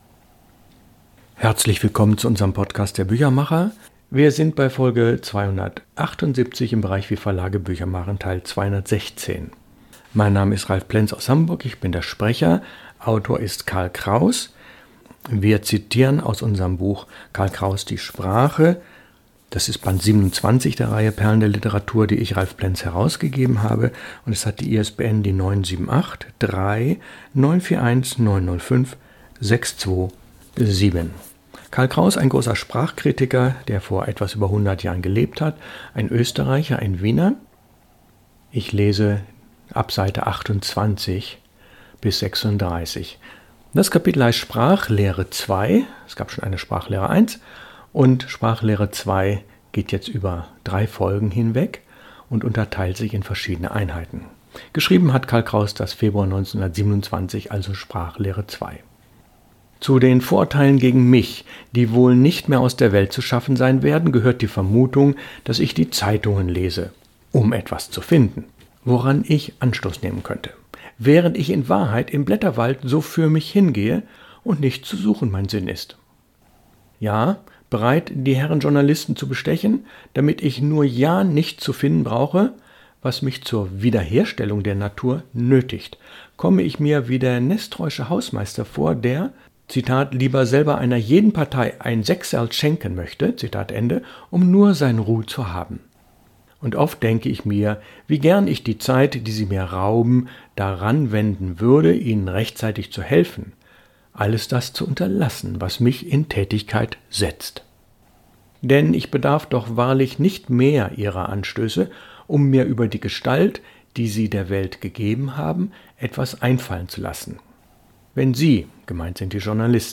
Lesung aus Karl Kraus – Die Sprache, Folge 2 von 4.